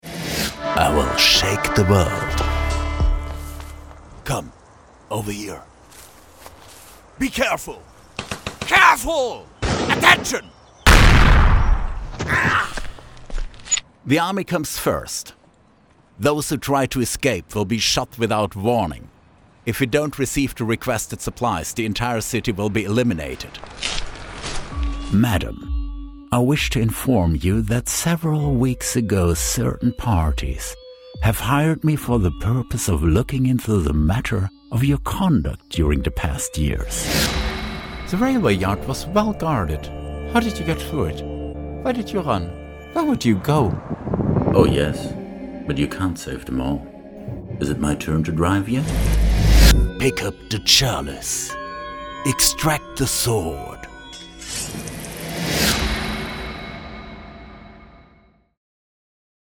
Male
Approachable, Authoritative, Character, Confident, Conversational, Cool, Corporate, Engaging, Friendly, Natural, Reassuring, Smooth, Versatile, Warm
Standard German, English with German/European accent
Microphone: Blue Bluebird SL